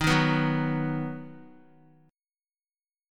D#m#5 chord